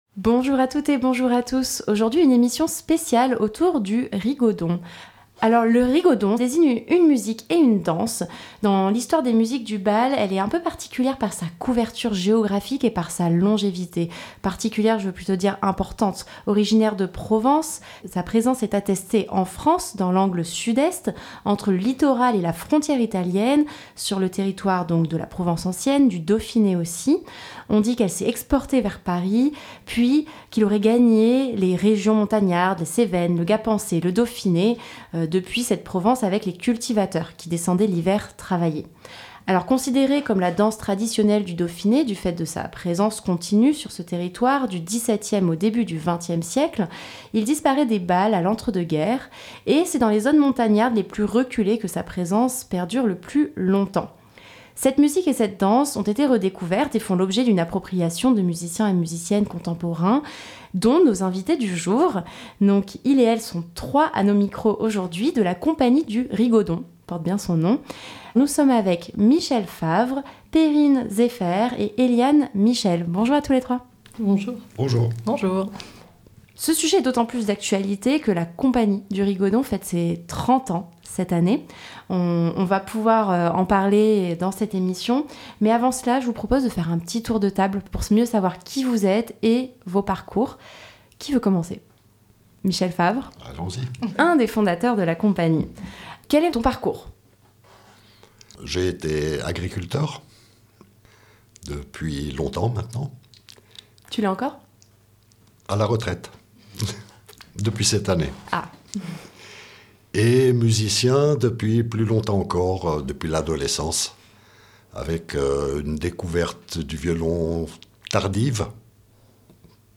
Aujourd'hui une émission spéciale autour du rigodon. Le rigodon est une musique, et une danse, remarquable dans l’histoire des musiques de bal par sa couverture géographique et par sa longévité.